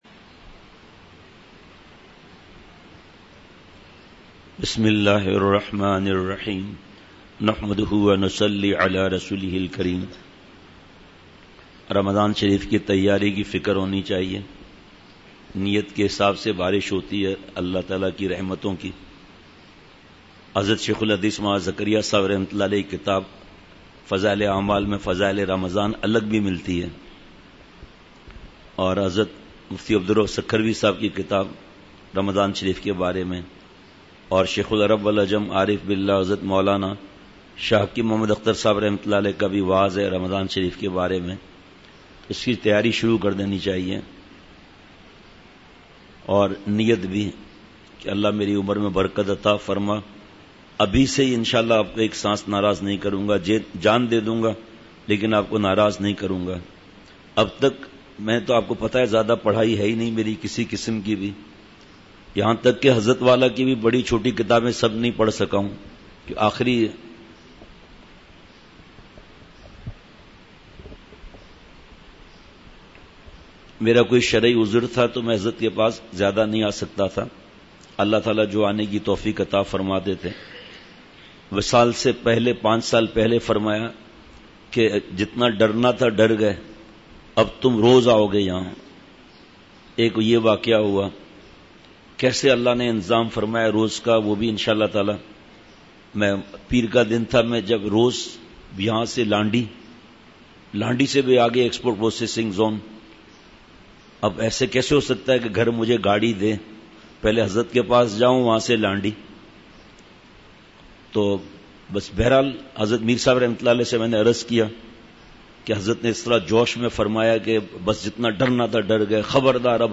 مجلس ۲۷ ۔اپریل ۲۰۱۹ء بعد فجر : اسلام کے دین فطرت ہونے کی دلیل !